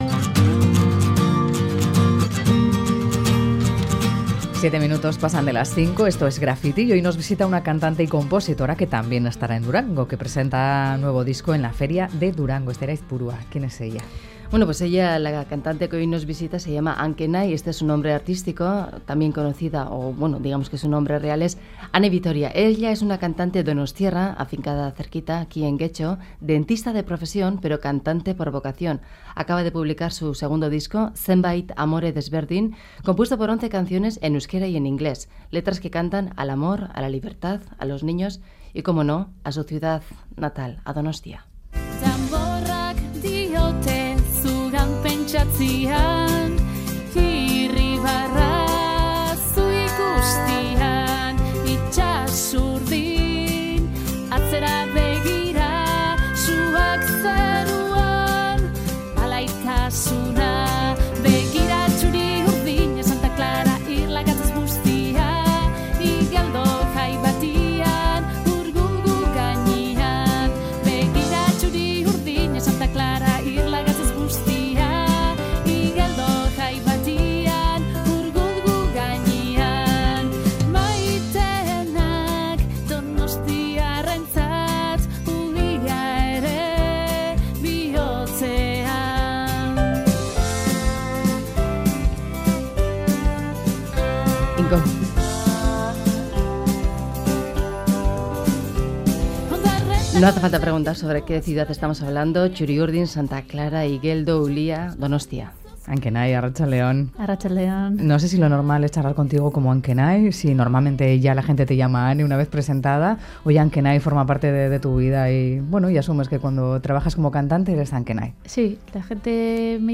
Un trabajo compuesto por 11 temas, en euskera, en el que suena la música celta, jazz, bossa nova....y con letras que cantan al amor y la libertad.